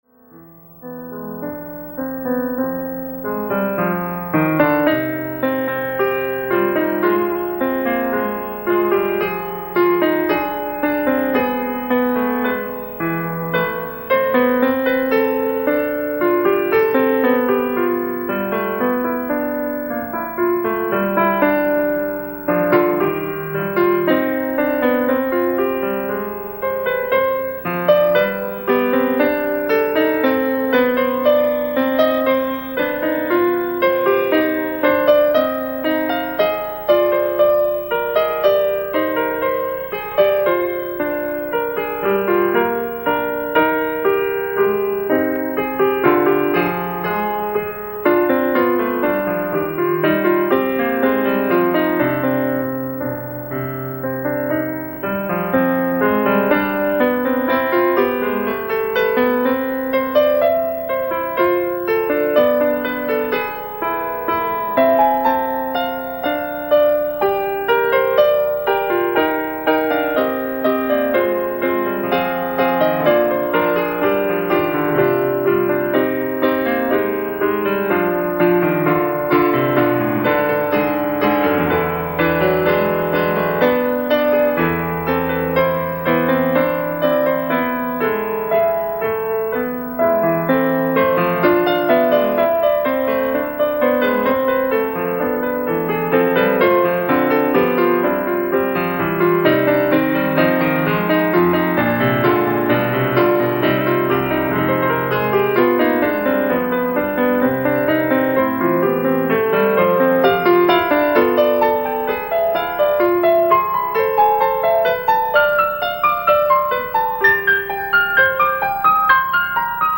ライブ・アット・マドリード、スペイン